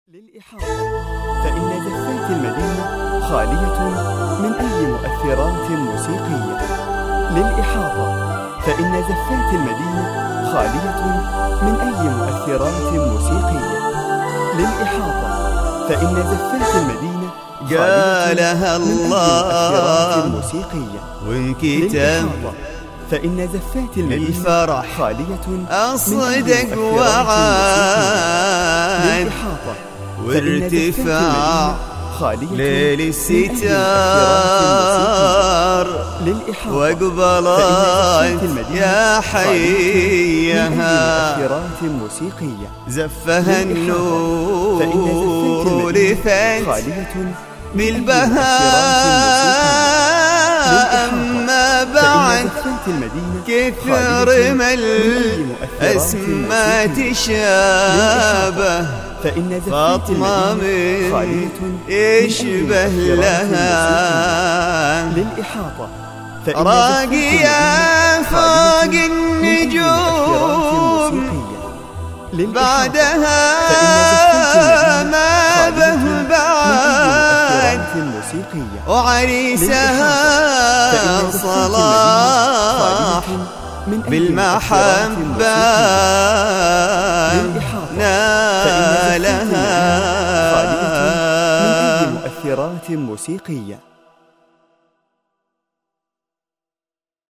الموالات الخليجية